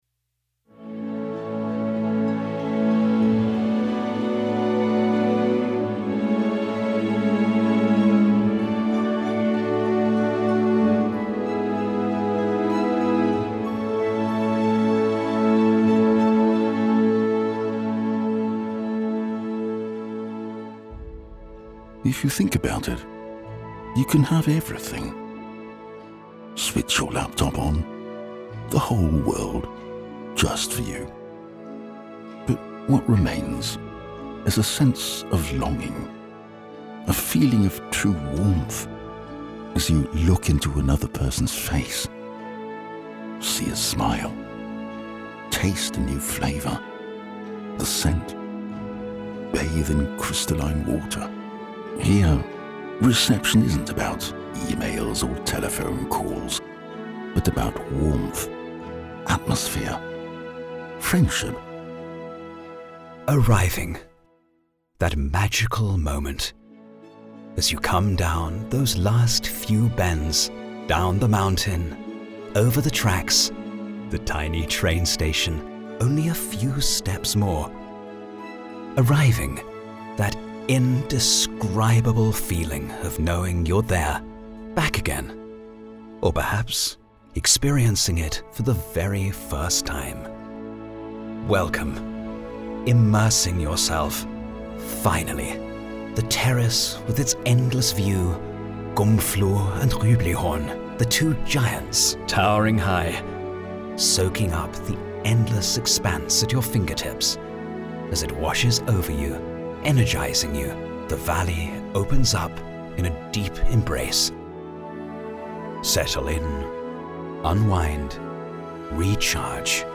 Our hotel history a moving audio experience